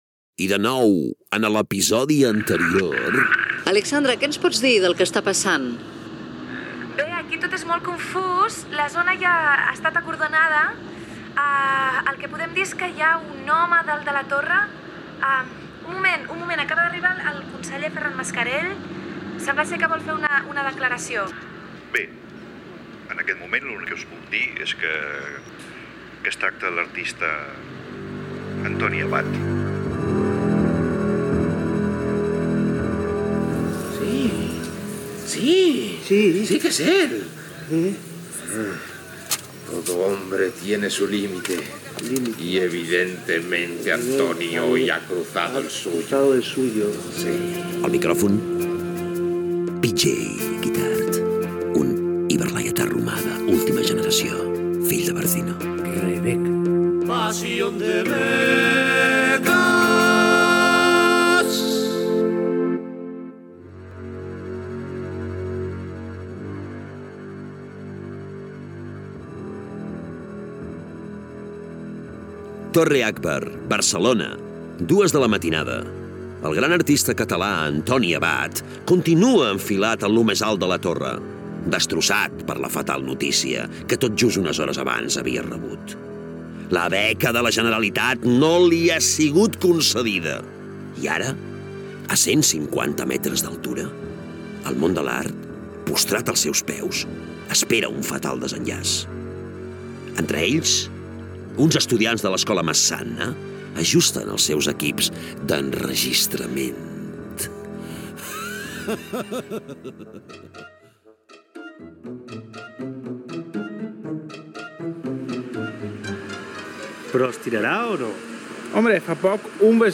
Gènere radiofònic Ficció